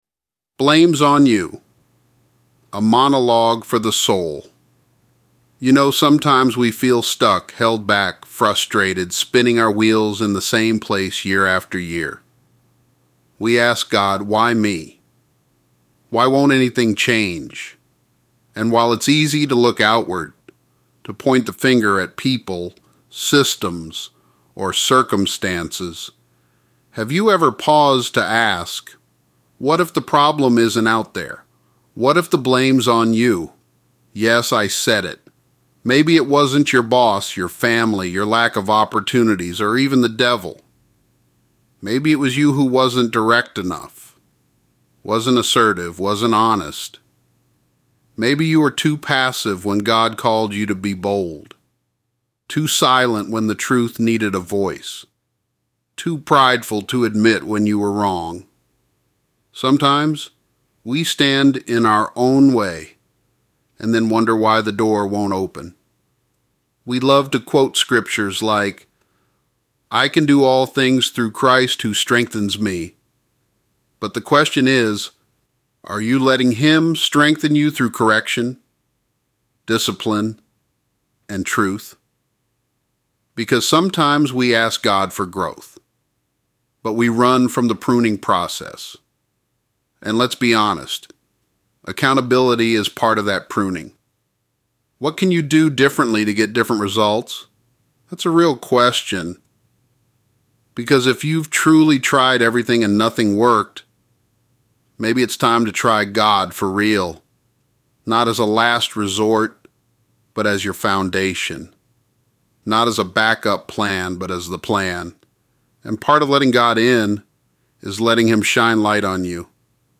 “Blame’s On You” – A Monologue for the Soul